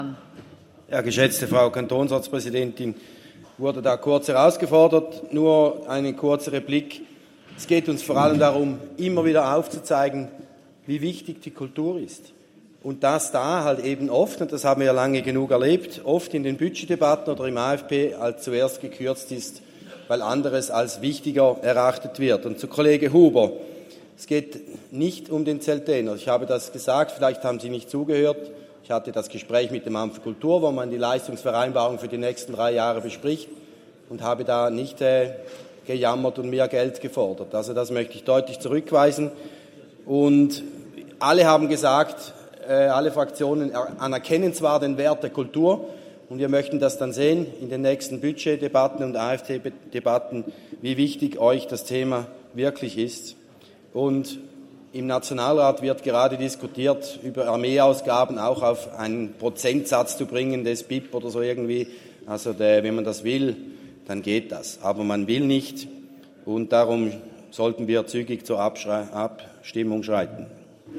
Session des Kantonsrates vom 29. April bis 2. Mai 2024, Aufräumsession
1.5.2024Wortmeldung